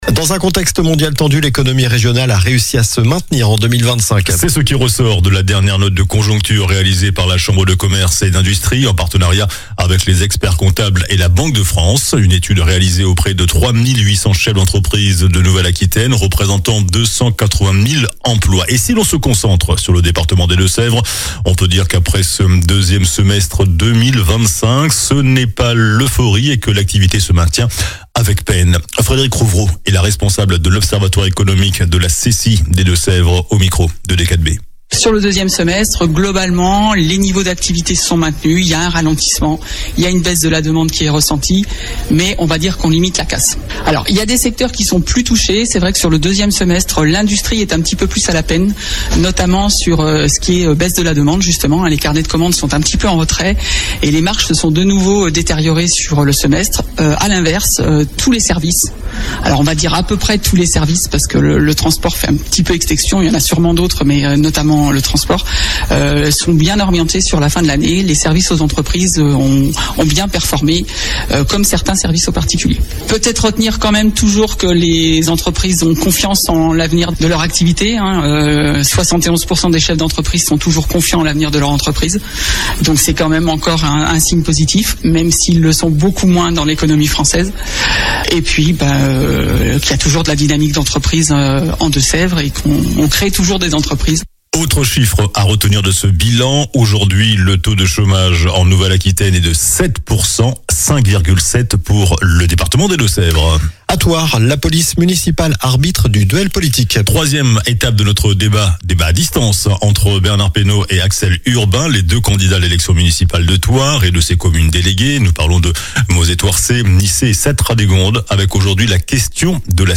JOURNAL DU MERCREDI 25 FEVRIER ( SOIR )